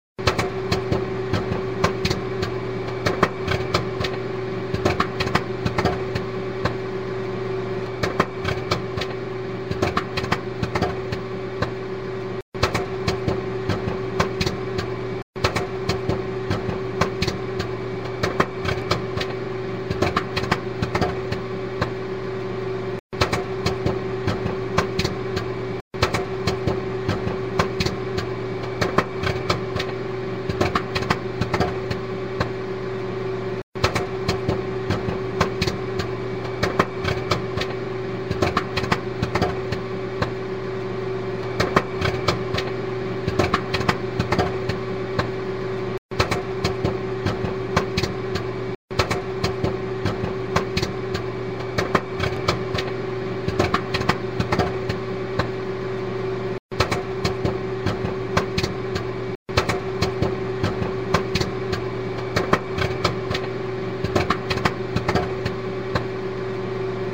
POPCORN SOUNDS v2
Tags: movie cinema popcorn